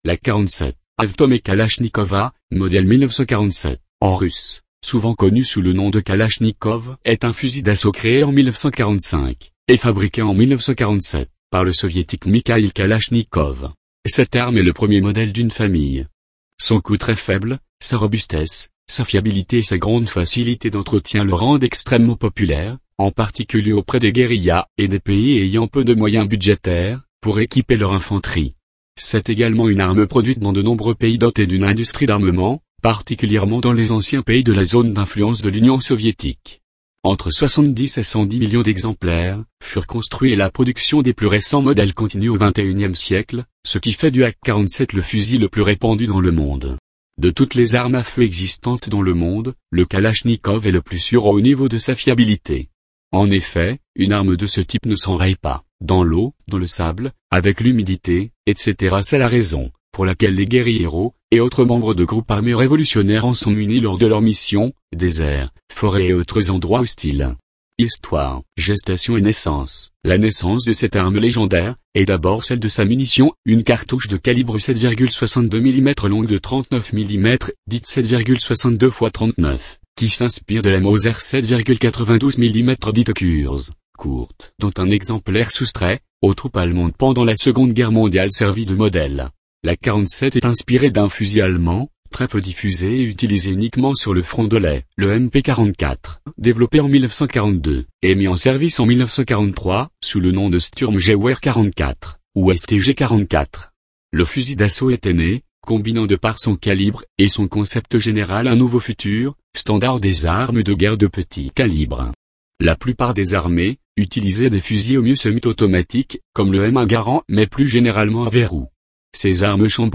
Voici quelques sujets parmi d'autres que j'ai cr�� avec la synth�se vocale de mon ordi.